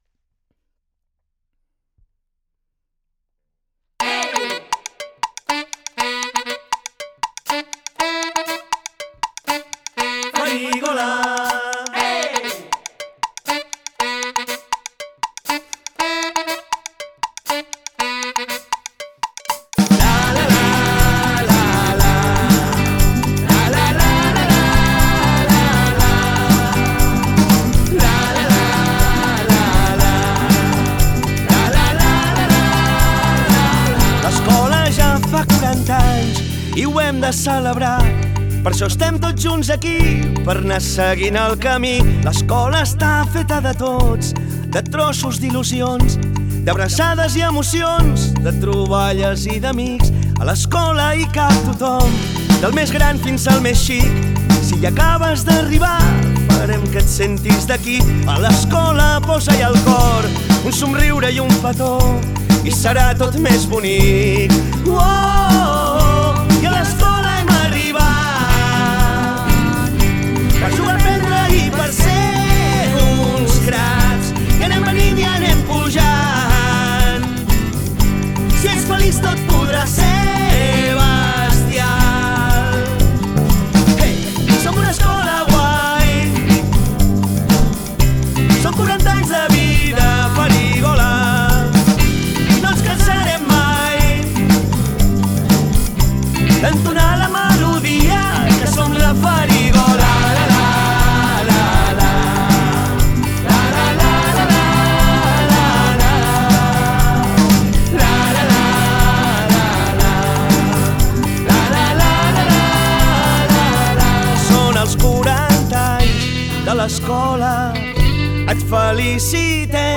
La cançó del 40 aniversari!